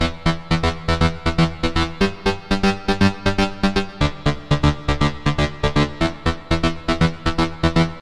loops basses dance 120 - 2
Téléchargez et écoutez tous les sons et loops de basses style dance music tempo 120bpm enregistrés et disponibles sur les banques de sons gratuites en ligne d'Universal-Soundbank pour tous les musiciens, cinéastes, studios d'enregistrements, DJs, réalisateurs, soundesigners et tous ceux recherchant des sons de qualité professionnelle.